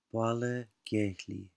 Captions English Irish pronunciation for the placename